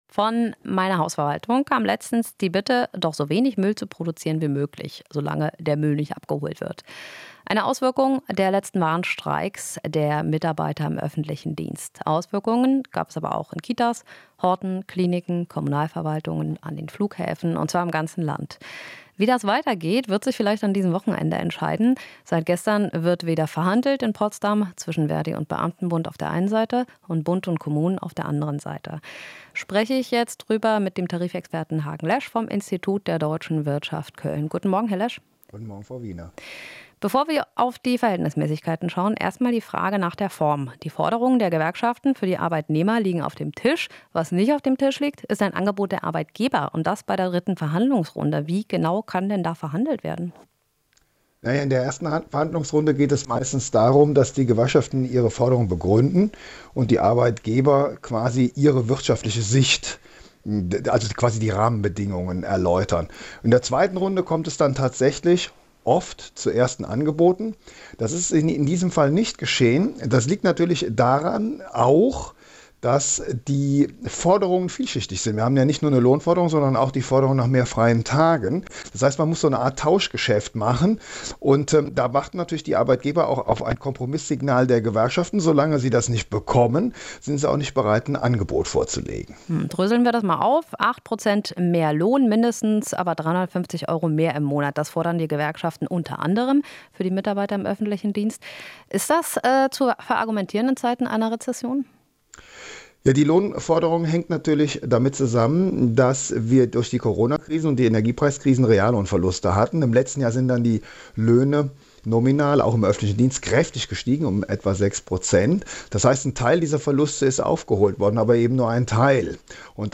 Interview - Öffentlicher Dienst: Dritte Verhandlungsrunde in Potsdam